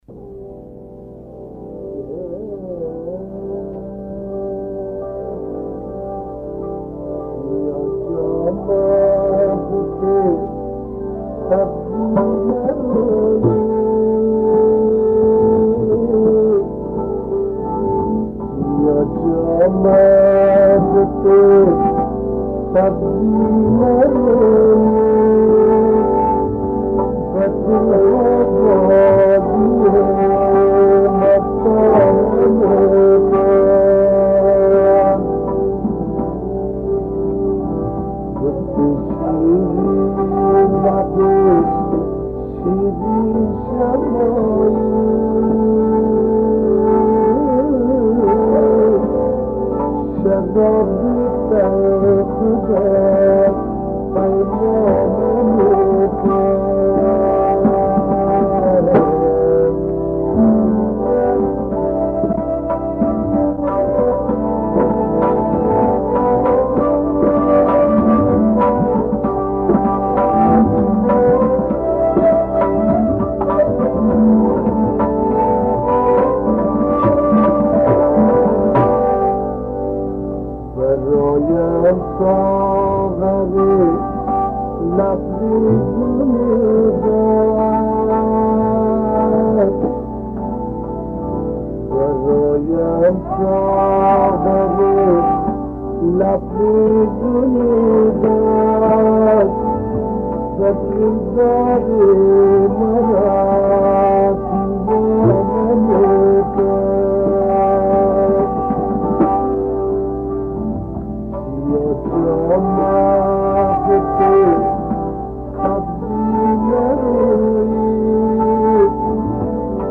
کیفیت آهنگ پایین است و متن شعر آن به دشواری پیاده شد.